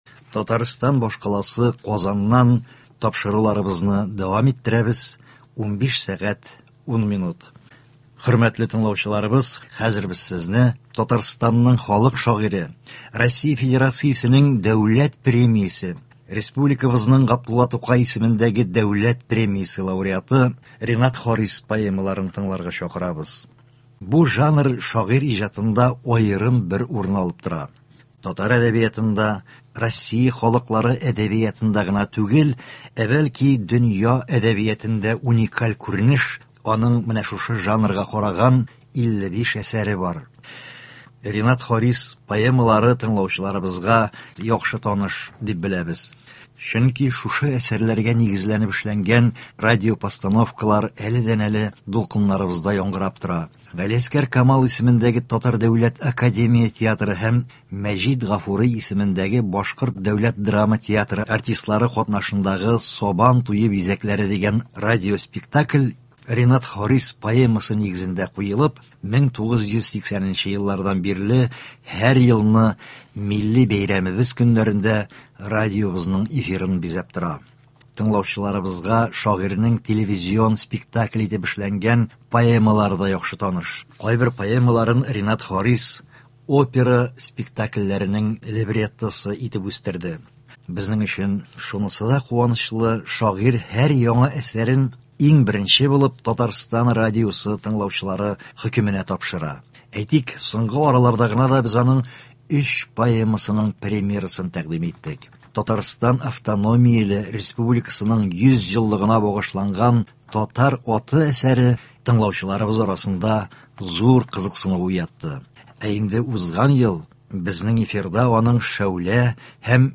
Игътибарыгызга Татарстанның халык шагыйре Ренат Харис поэмаларын тәкъдим итәбез. Аларны авторы үзе укый.